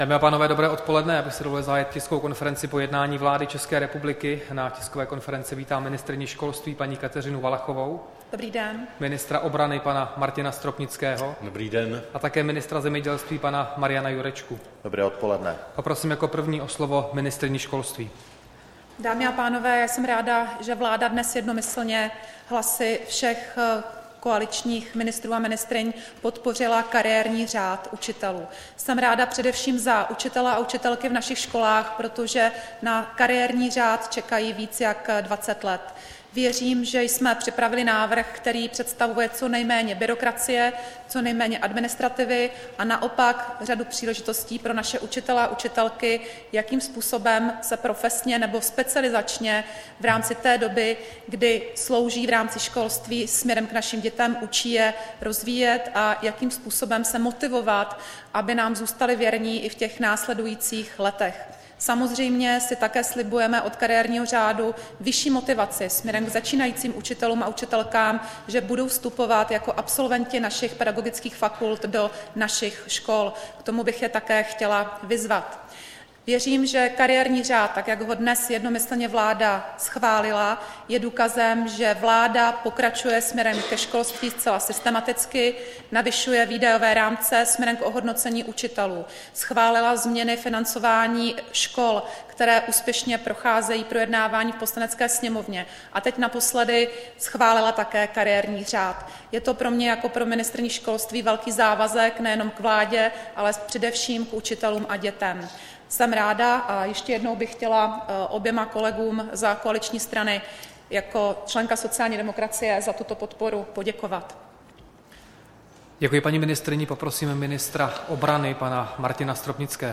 Tisková konference po jednání vlády, 7. listopadu 2016